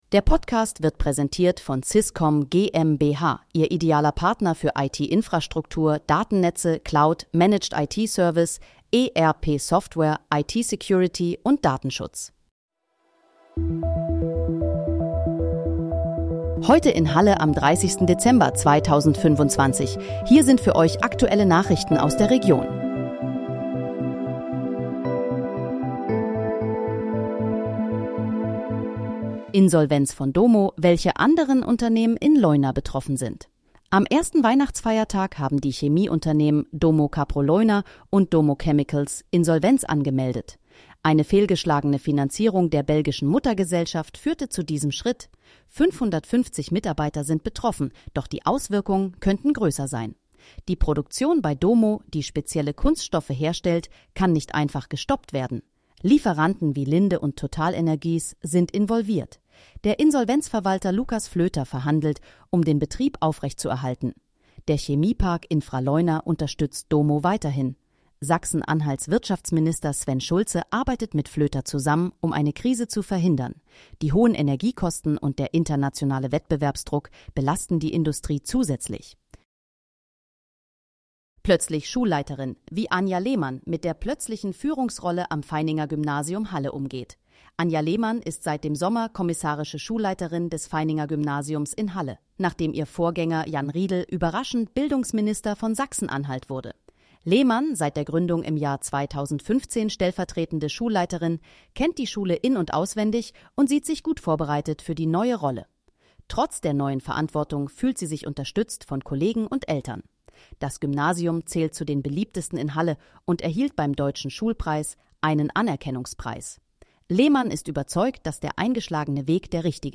Heute in, Halle: Aktuelle Nachrichten vom 30.12.2025, erstellt mit KI-Unterstützung
Nachrichten